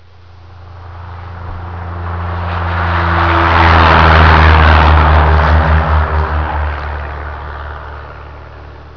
avion.wav